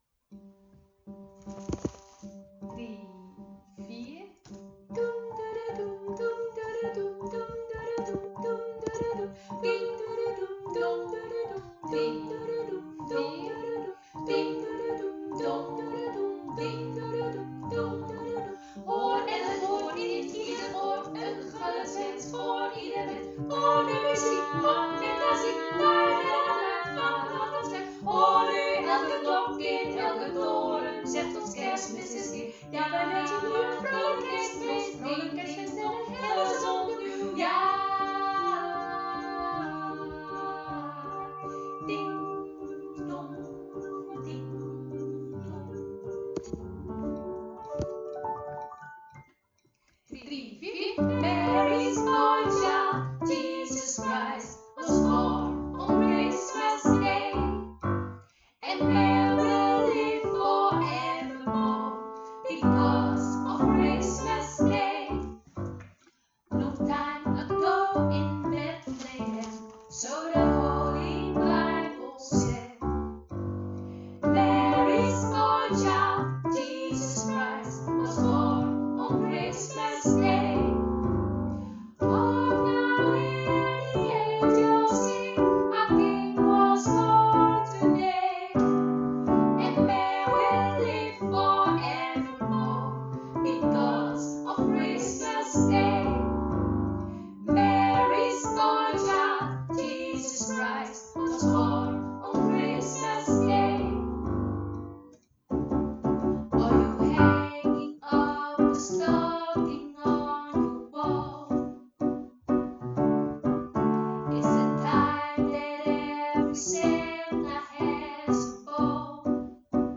Alles-kerstmedley.wav